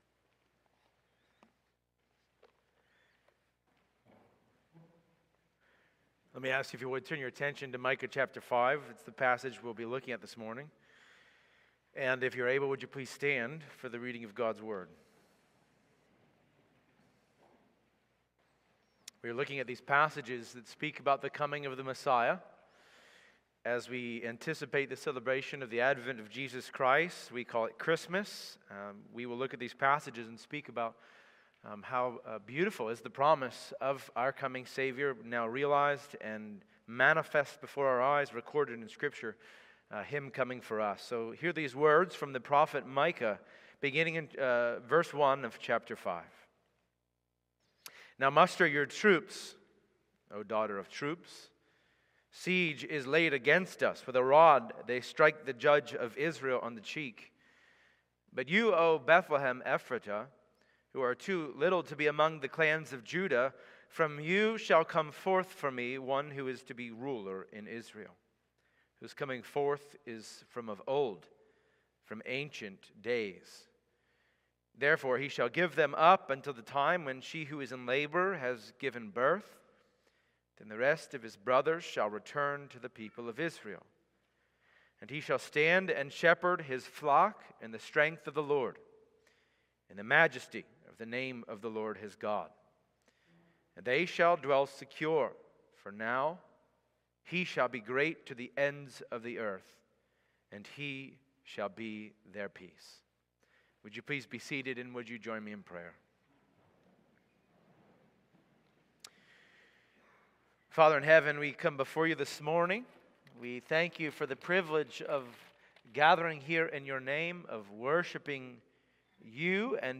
December-8-Worship-Service.mp3